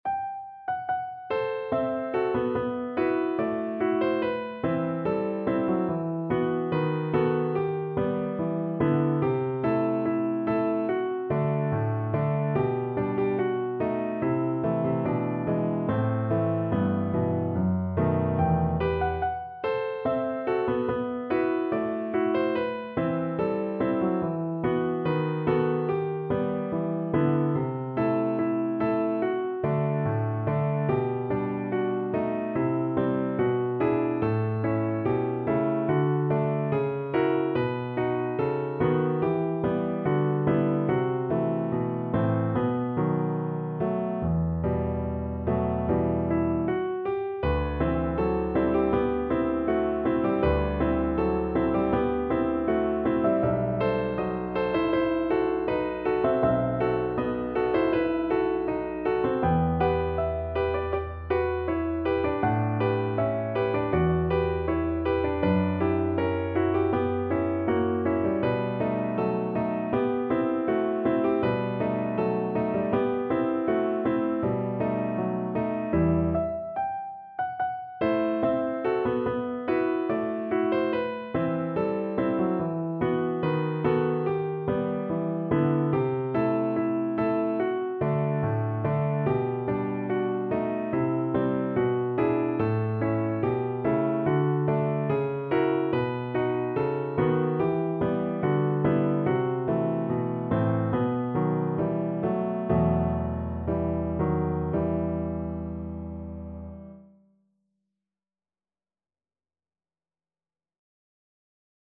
No parts available for this pieces as it is for solo piano.
~ = 72 Andantino (View more music marked Andantino)
2/4 (View more 2/4 Music)
Piano  (View more Intermediate Piano Music)
Classical (View more Classical Piano Music)